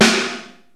PLATE SNR.wav